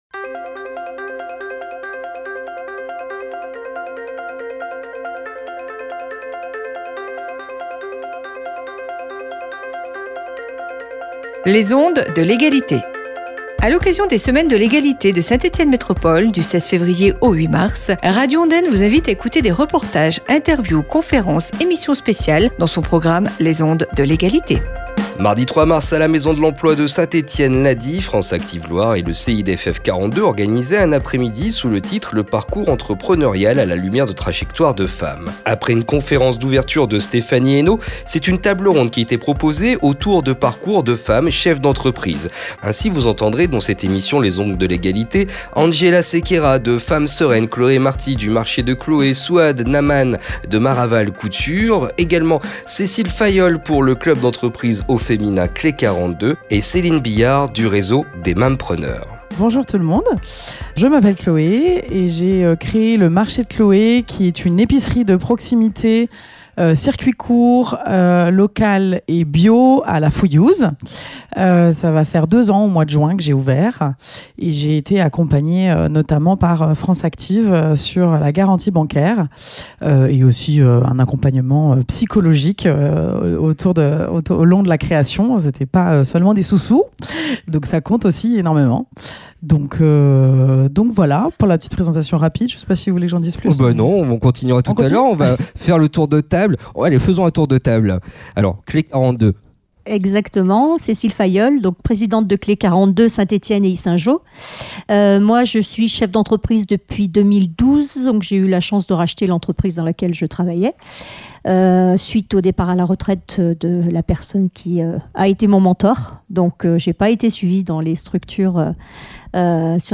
LE PARCOURS ENTREPRENEURIAL A LA LUMIERE DE TRAJECTOIRES DE FEMMES, table ronde, les Ondes de l’Égalité.
Radio Ondaine animait une table ronde autour de témoignages et parcours de femmes cheffes d’entreprises.